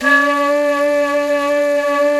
Index of /90_sSampleCDs/Roland LCDP04 Orchestral Winds/FLT_Jazz+Singin'/FLT_Singin'Flute